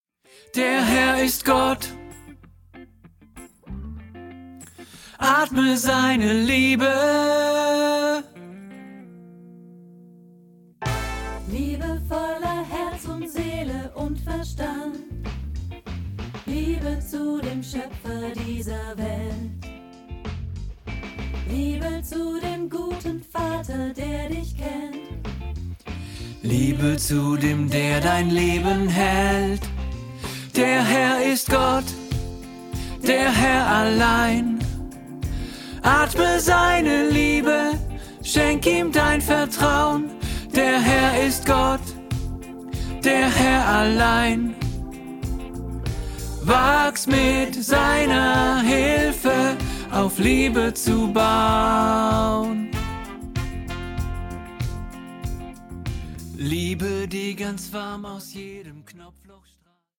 Lern-Tenor1